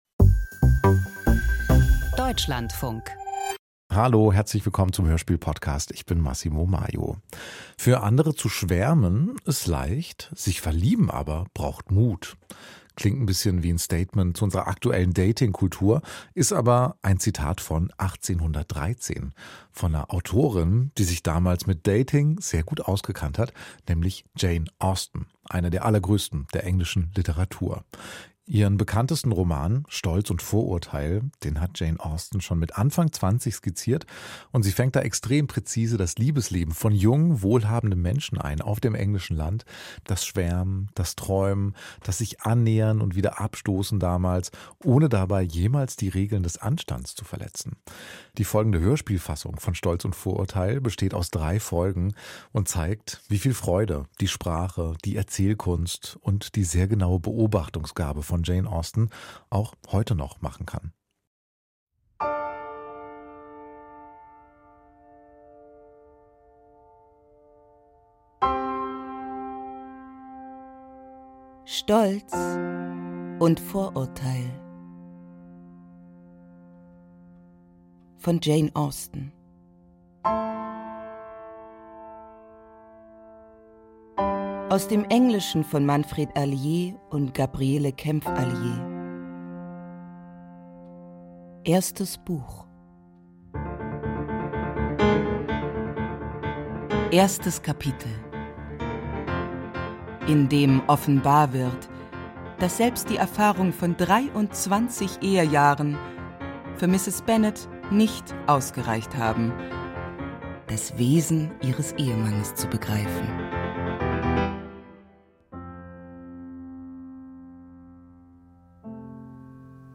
Der Klassiker der Weltliteratur als dreiteiliges Hörspiel.